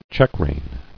[check·rein]